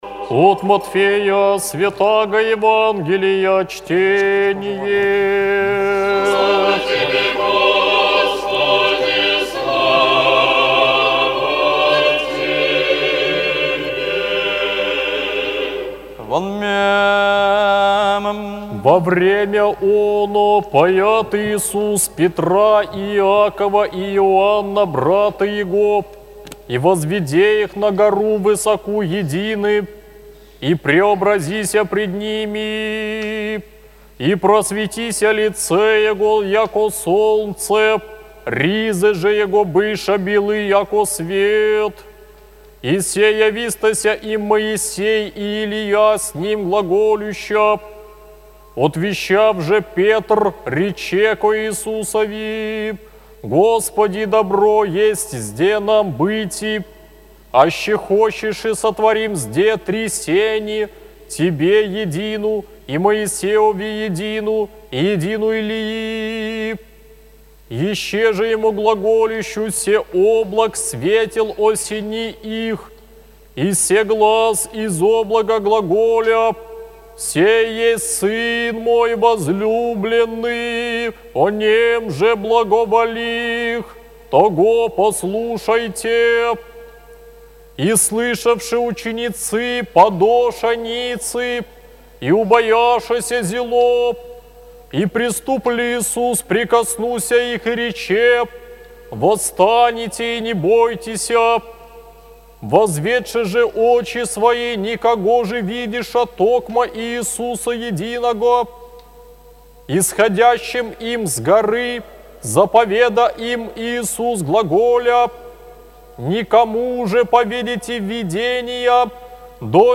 ЕВАНГЕЛЬСКОЕ ЧТЕНИЕ НА литургии